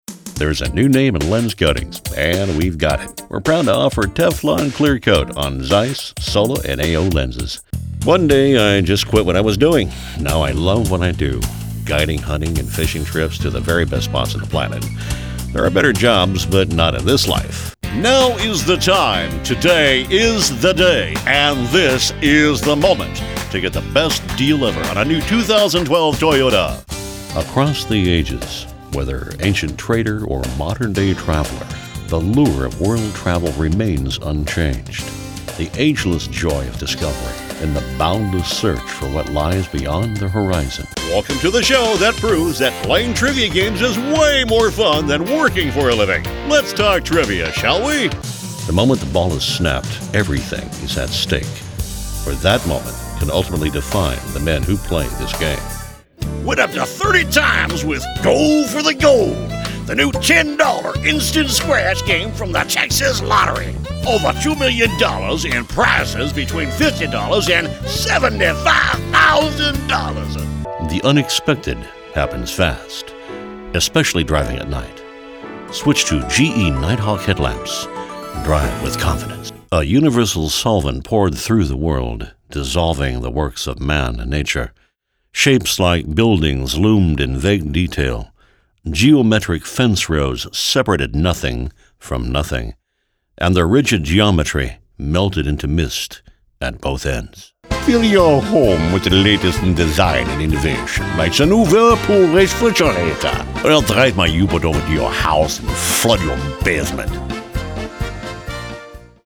Mature, Gravelly, Subtle